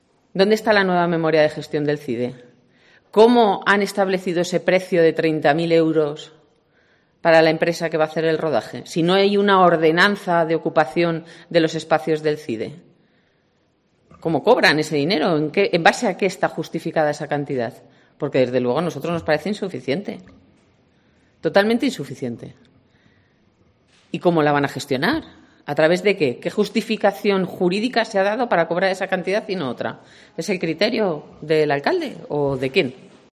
Clara Martín, portavoz socialista en el Ayuntamiento de Segovia, sobre el CIDE